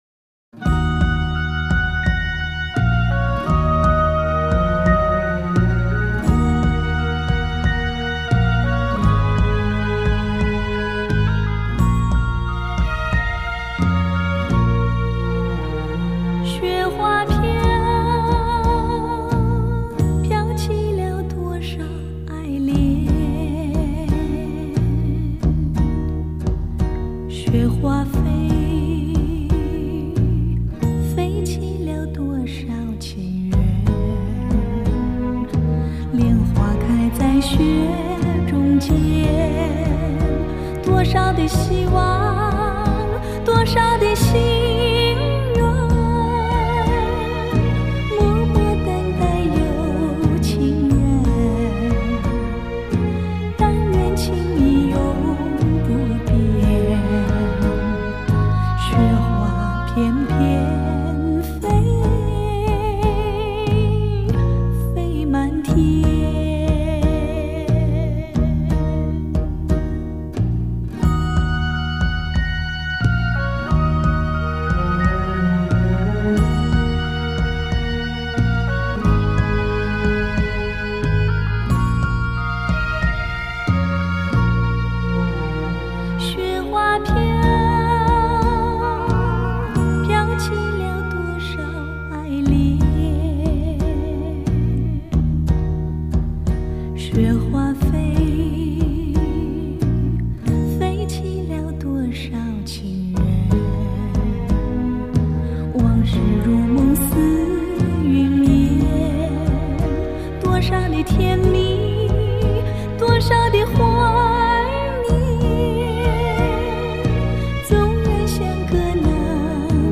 试听中感觉无任何染色元素，如杯夏天的山泉甘甜而真我。